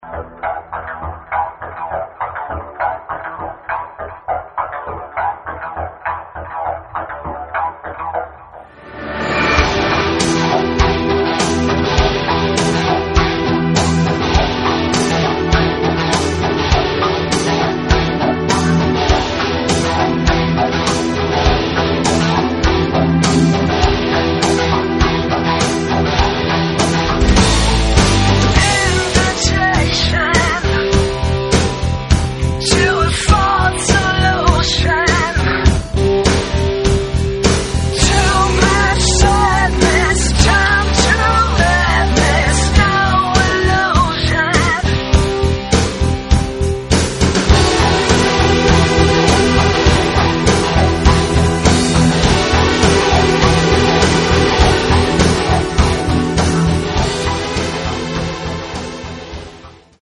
Рок
Этот альбом получился более мощным и страстным.